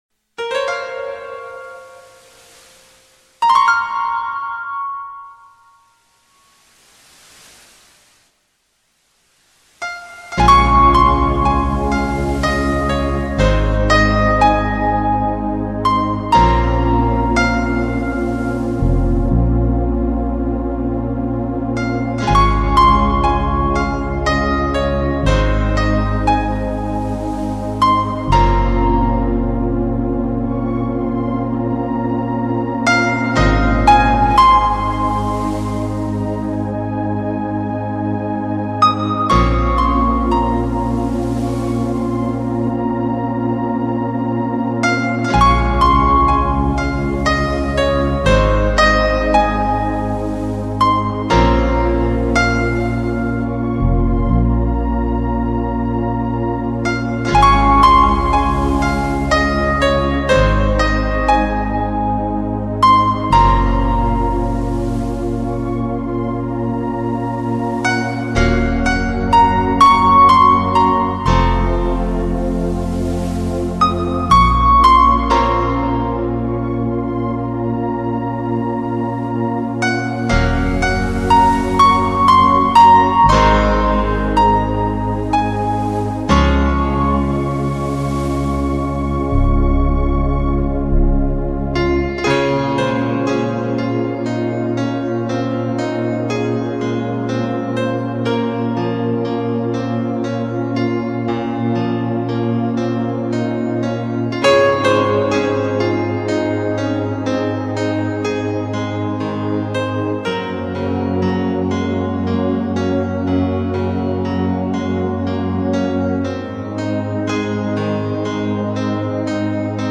专辑格式：DTS-CD-5.1声道
于大自然收集的静致元声，于听觉上舒适的放松，轻松小憩睡前必备，仿佛漫步在森林、原野、山谷、海滩……